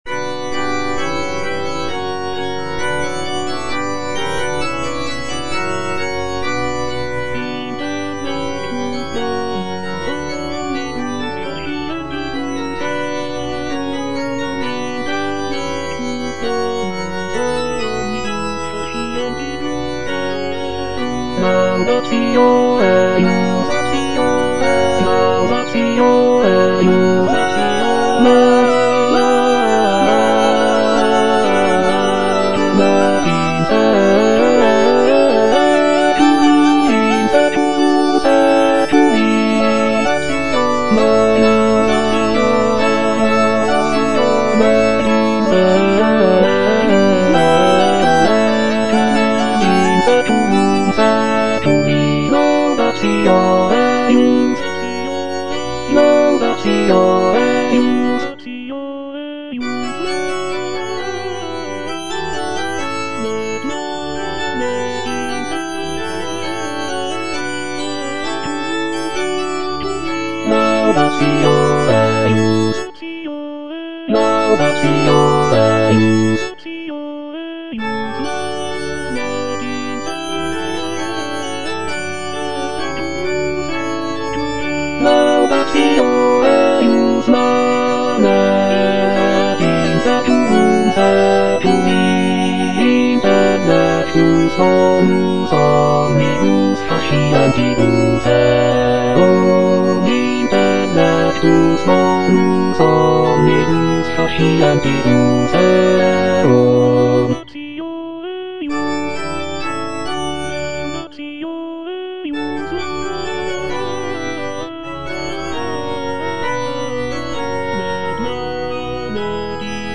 M.R. DE LALANDE - CONFITEBOR TIBI DOMINE Intellectus bonus omnibus (bass) (Emphasised voice and other voices) Ads stop: auto-stop
"Confitebor tibi Domine" is a sacred choral work composed by Michel-Richard de Lalande in the late 17th century.
Lalande's composition features intricate polyphony, lush harmonies, and expressive melodies, reflecting the Baroque style of the period.